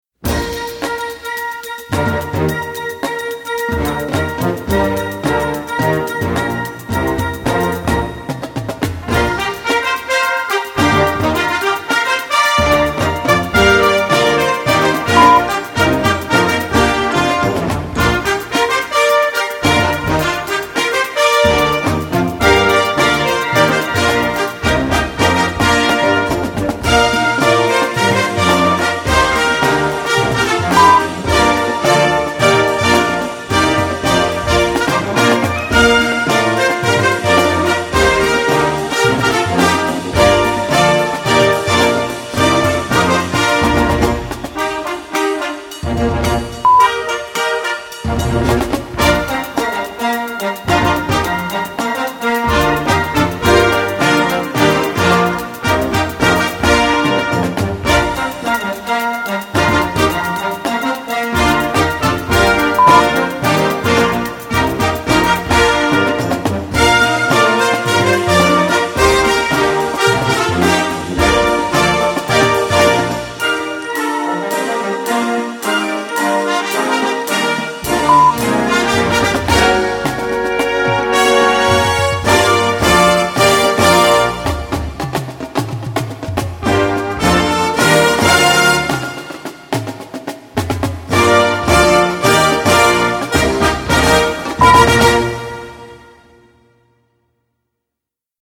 Marching-Band
Besetzung: Blasorchester